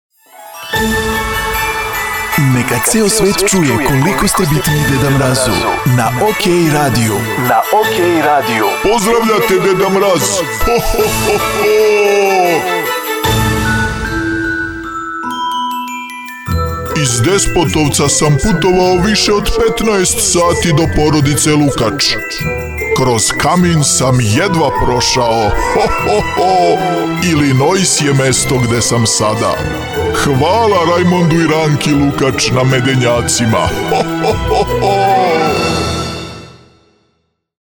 Neka vas ili vama bitnu osobu pozdravi Deda Mraz i neka pozdrav čuje ceo svet…
Tu smo da osmislimo kratak džingl koji će da efektno nagovesti nešto o osobi koju pominjemo .